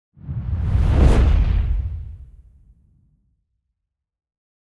fire_woosh.ogg